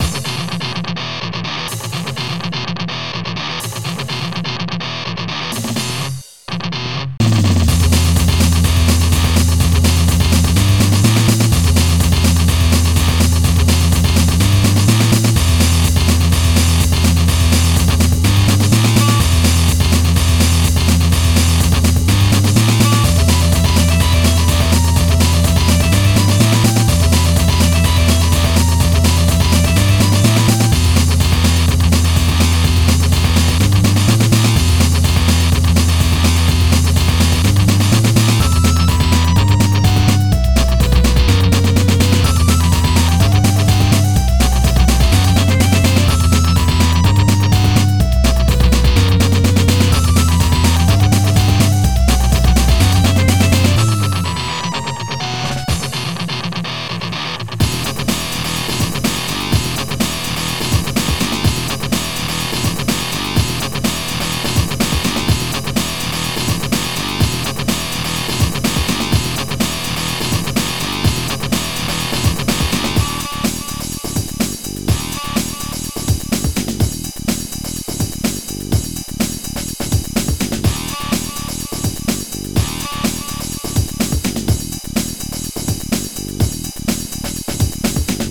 xm (FastTracker 2 v1.04)
FastTracker v2.00 XM 1.04